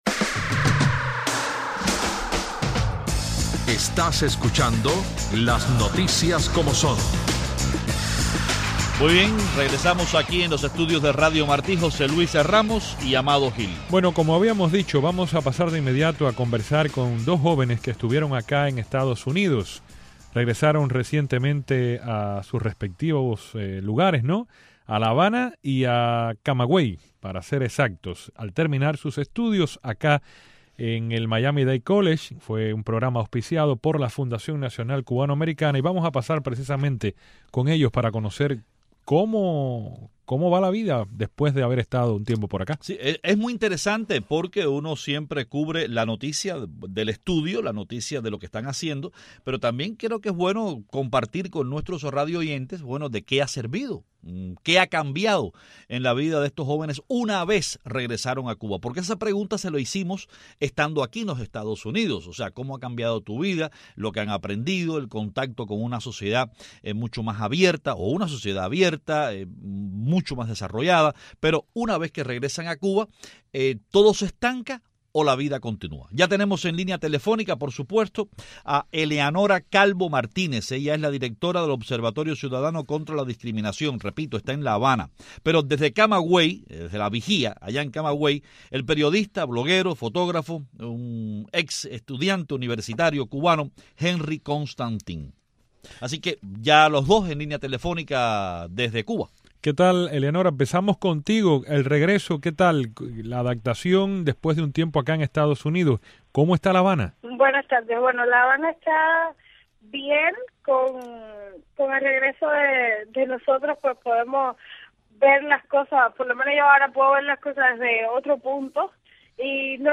Hoy hablamos con dos jóvenes cubanos de la sociedad civil, quienes tuvieron la oportunidad de estudiar en el Miami Dade College a través de un programa auspiciado por la Fundación Nacional Cubano Americana.